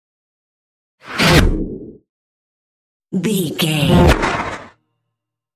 Scifi whoosh pass by x2
Sound Effects
futuristic
pass by